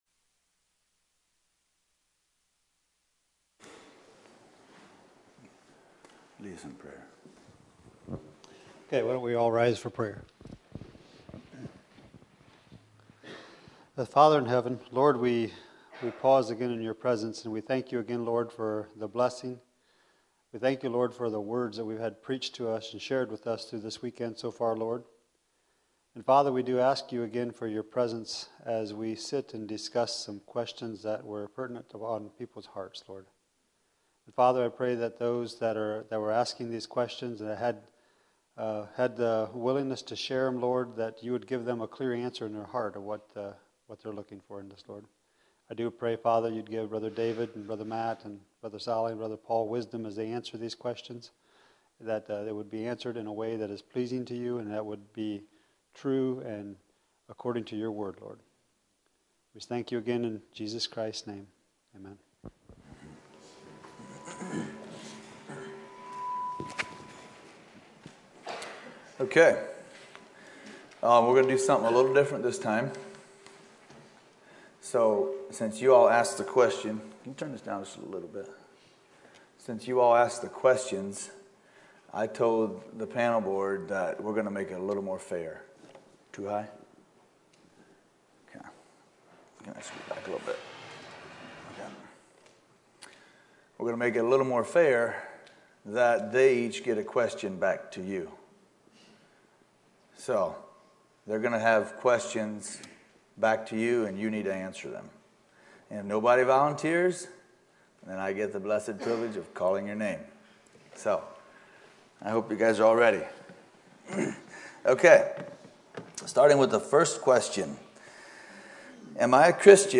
2024 Youth Meetings | Panel Discussion